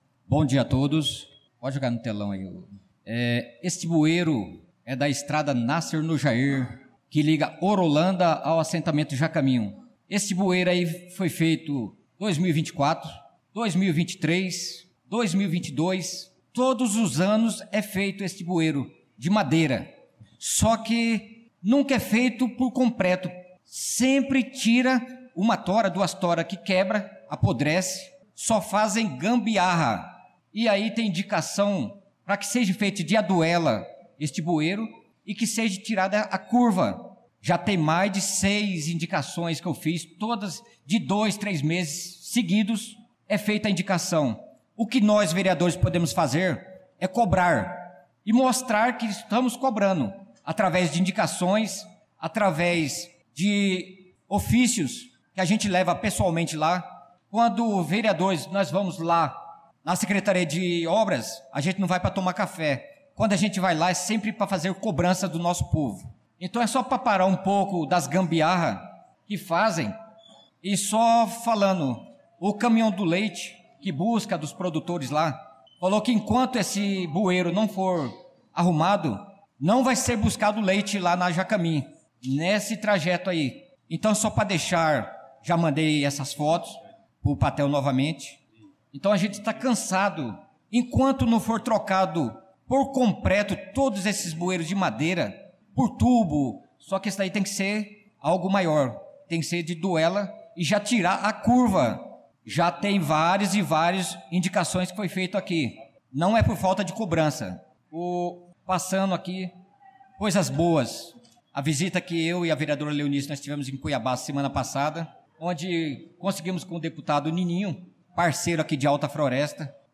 Pronunciamento do vereador Naldo da Pista na Sessão Ordinária do dia 06/03/2025